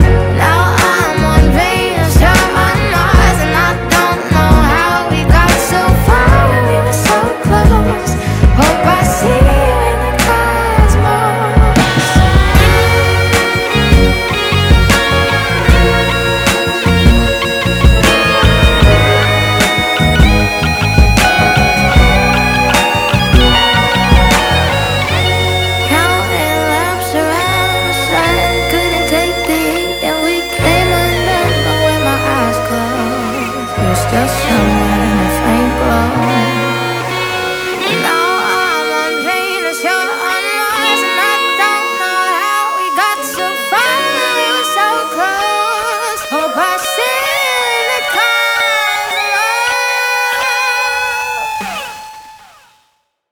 Ending Solo Riff